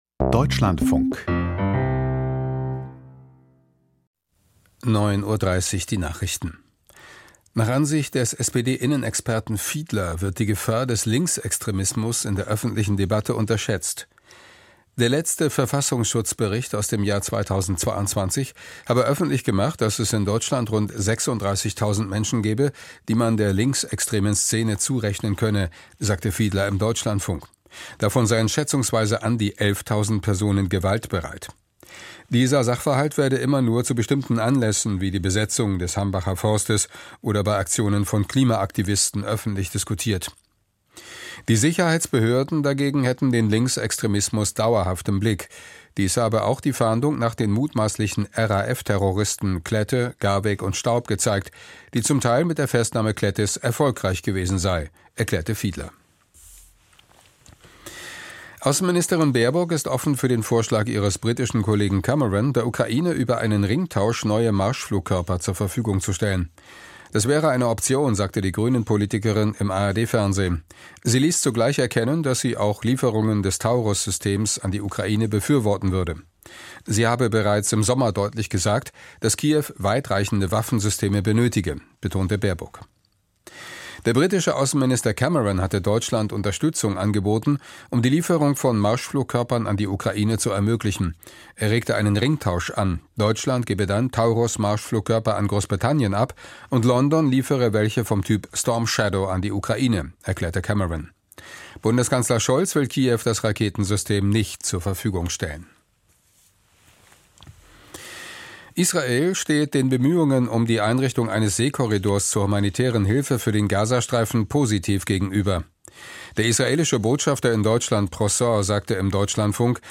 Russlands Rüstung: Waffenexporte eingebrochen - Interview